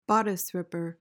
PRONUNCIATION:
(BOD-is rip-uhr)